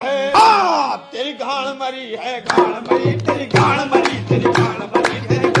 teri gand mari Meme Sound Effect